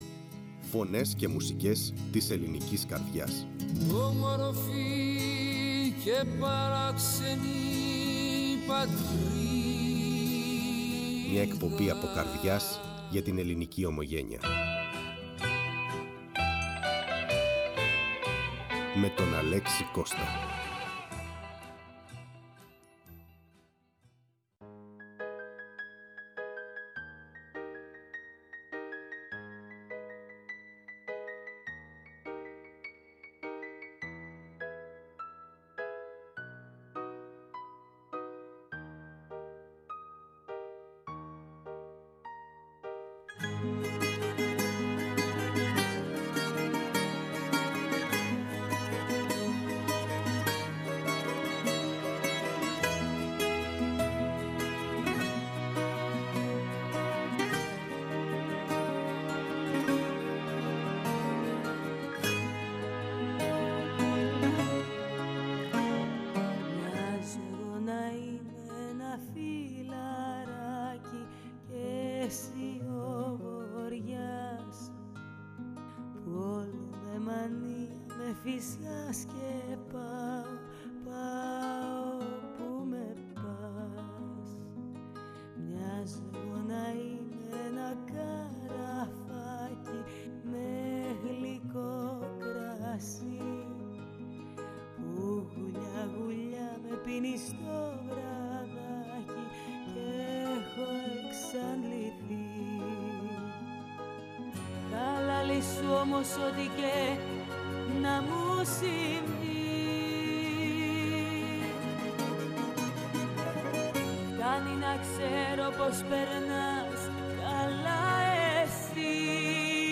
Ήταν μια συνέντευξη που θα μείνει.